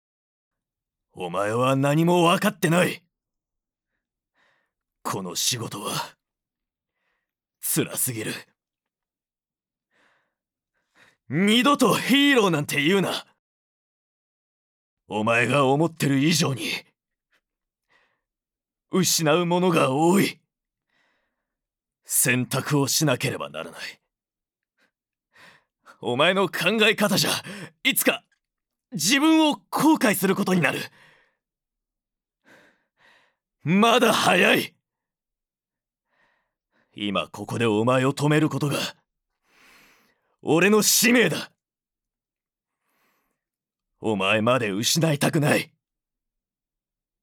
ボイスサンプル
●セリフ③40歳前後のワイルドなアクションヒーロー